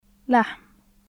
無声・咽頭・摩擦音/ħ/
لحم /laħm/